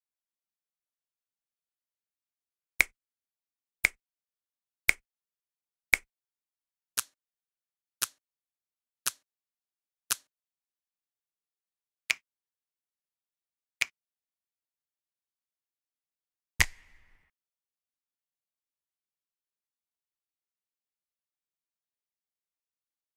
دانلود آهنگ بشکن 1 از افکت صوتی انسان و موجودات زنده
دانلود صدای بشکن 1 از ساعد نیوز با لینک مستقیم و کیفیت بالا
جلوه های صوتی